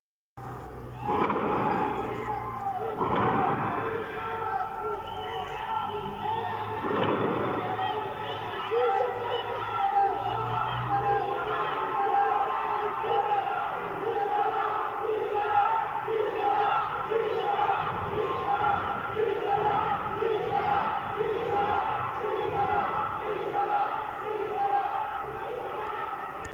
بهاران سنندج الان